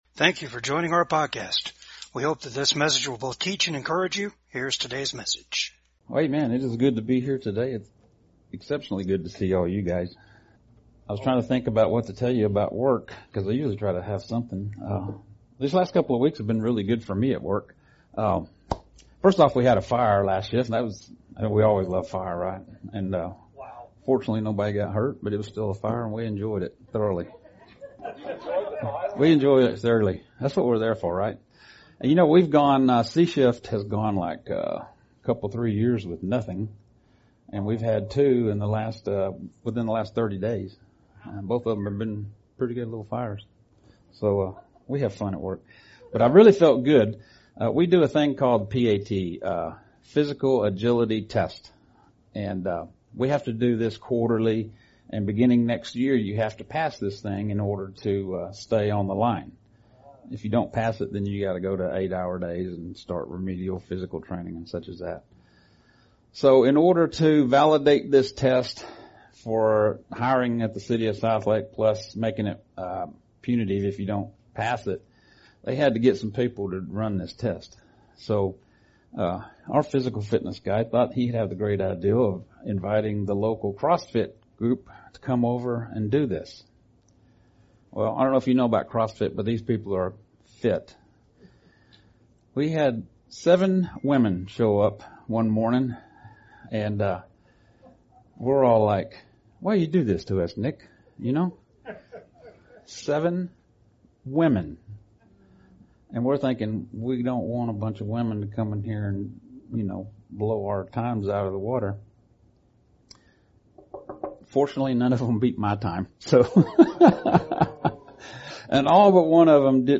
Judges 11-12 Service Type: VCAG WEDNESDAY SERVICE REJECTION BY MAN IS NOT THE END.